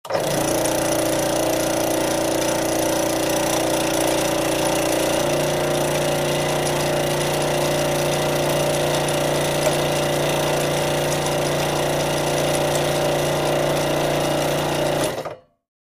Scrollsaws
in_scrollsaw_run_01_hpx
Scroll saw idles, cuts thin wood and shuts off.